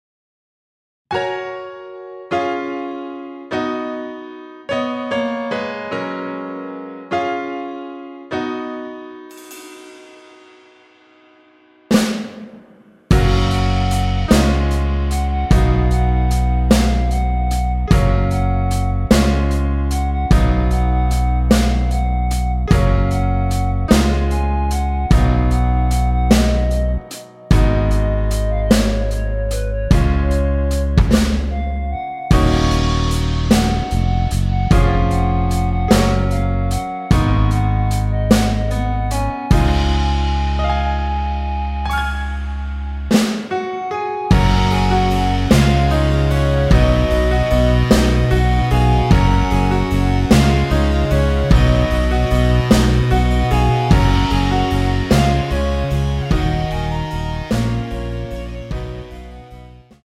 원키에서(+6)올린 멜로디 포함된 MR입니다.
F#
앞부분30초, 뒷부분30초씩 편집해서 올려 드리고 있습니다.
중간에 음이 끈어지고 다시 나오는 이유는